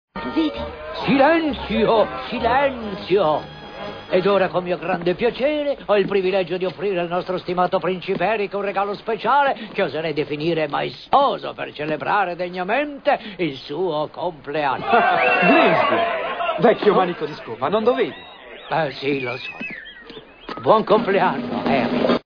nel film d'animazione "La Sirenetta", in cui doppia Grimsby.